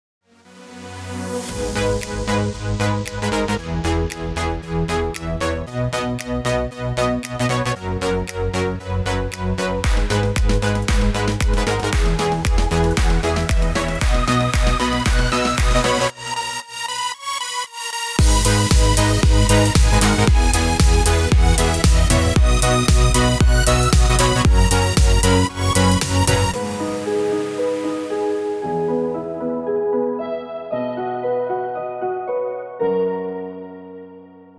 Featured in Instrumental Ringtones